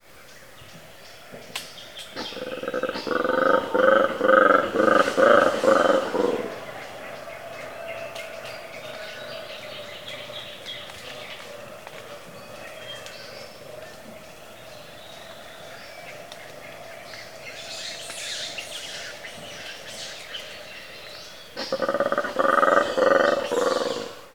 BLACK-AND-WHITE COLOBUS MONKEYS, REC. KAKAMEGA, KENYA, AFRICA
BLACK-AND-WHITE-COLOBUS-MONKEYS.mp3